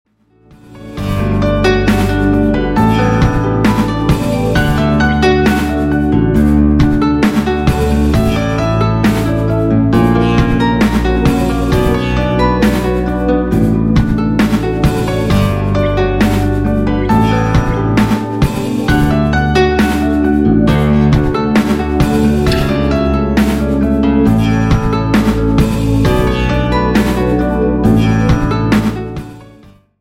AMBIENT MUSIC  (4.42)